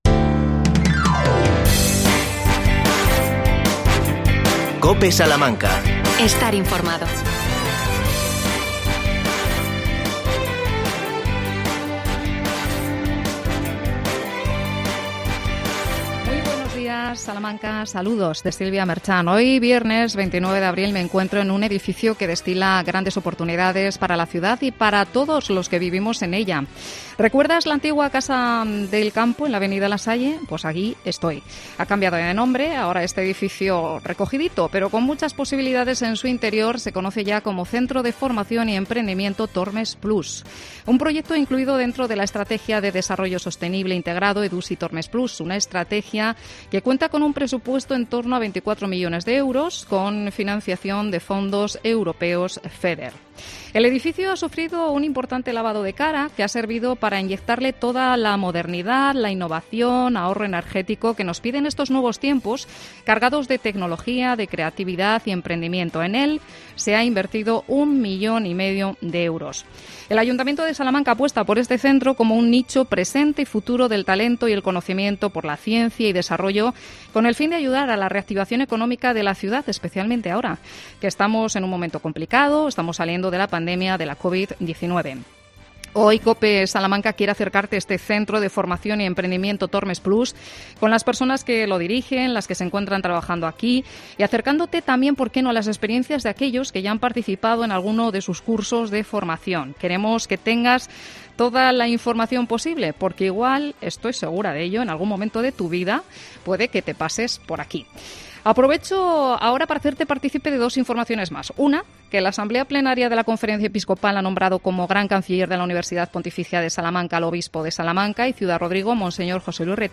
AUDIO: Programa especial desde el centro de formación y oportunidades Tormes EDUSI+